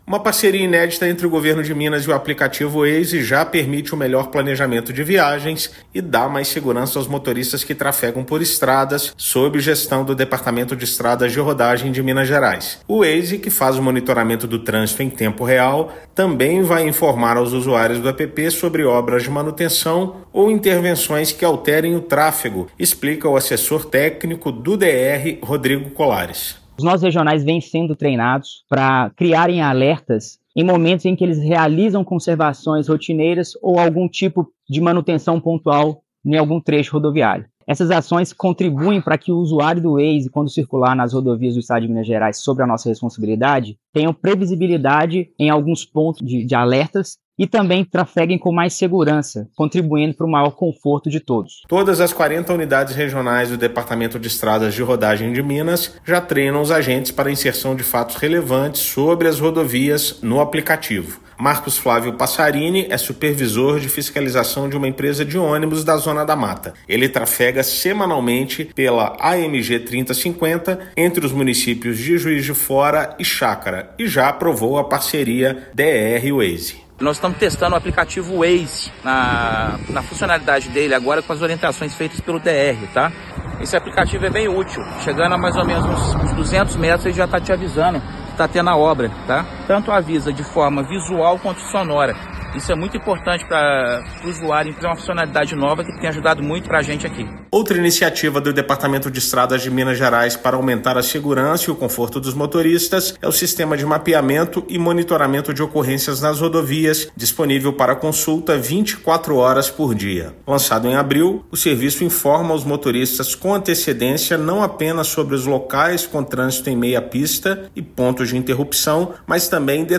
Medida permitirá melhor planejamento de viagens e aumentará segurança nos deslocamentos em estradas sob gestão do Departamento de Estradas de Rodagem de Minas Gerais (DER-MG). Ouça matéria de rádio.